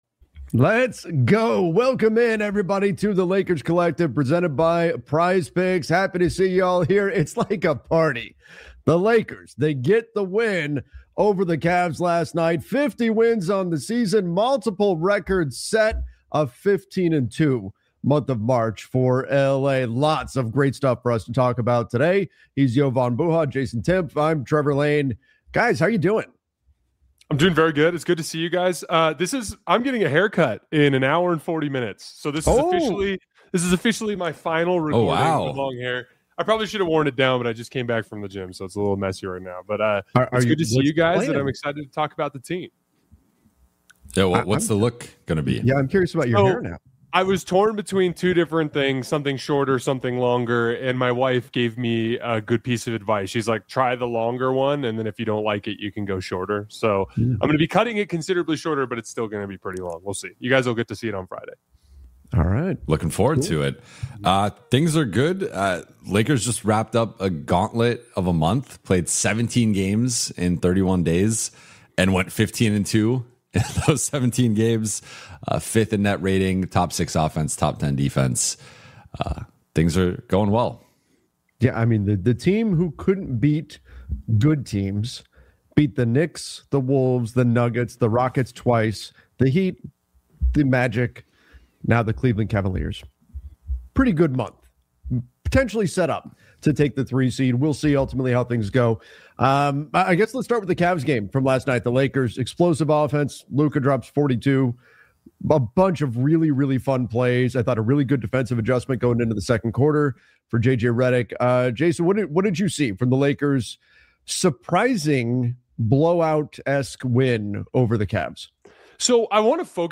Welcome to the Lakers Collective — a weekly Los Angeles Lakers roundtable